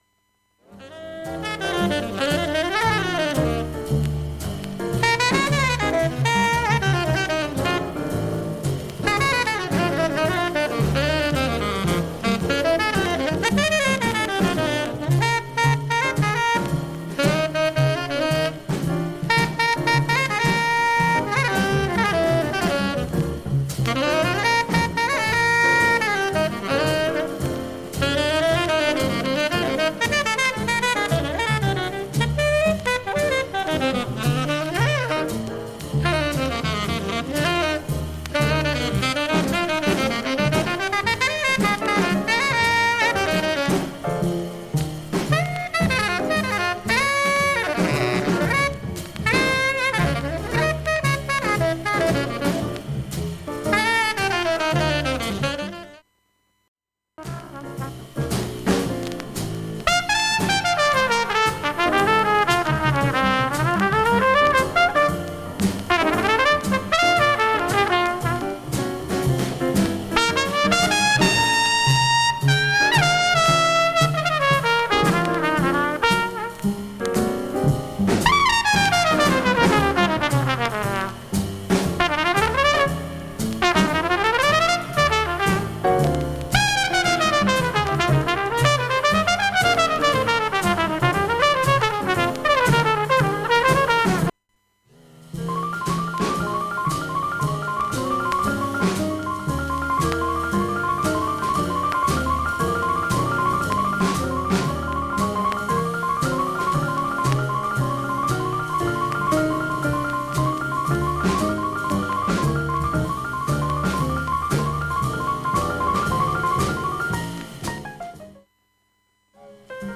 全体に軽いチリプツはいる程度です。
A-1中盤に8回プツ出ます。
A-1後半に3ミリスレでプツ出ます。
現物の試聴（上記録音時間6分）できます。音質目安にどうぞ